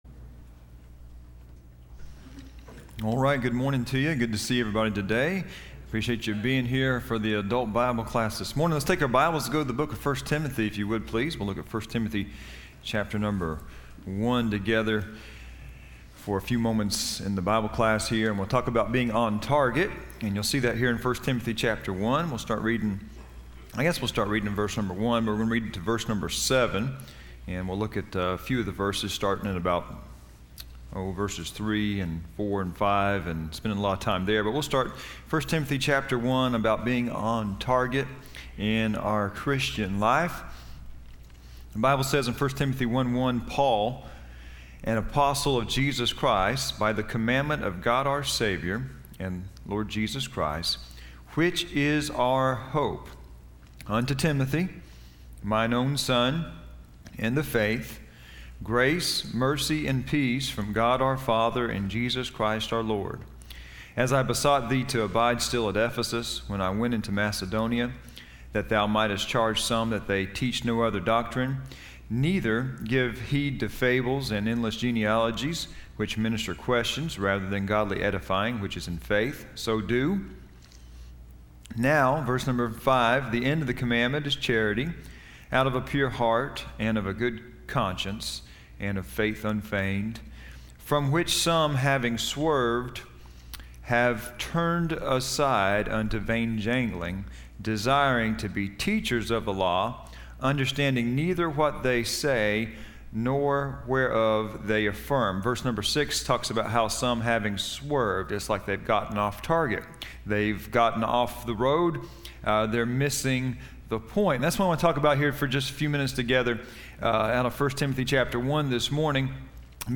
Service Type: Sunday School